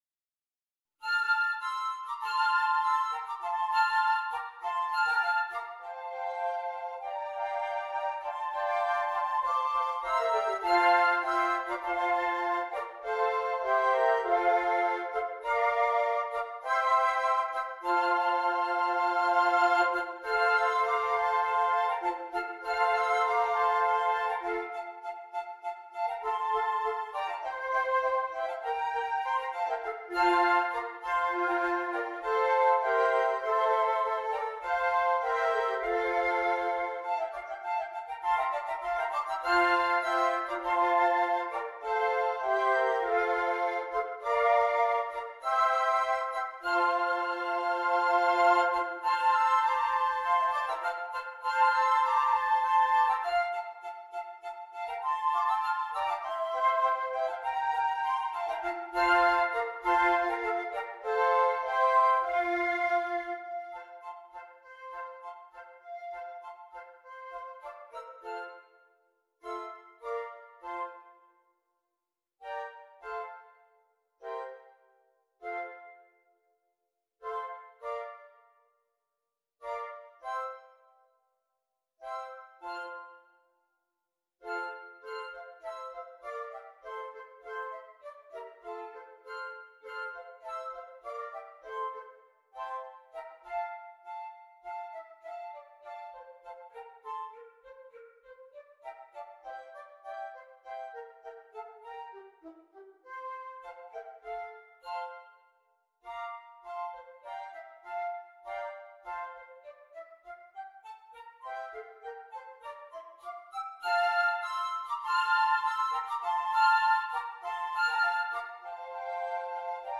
6 Flutes
Traditional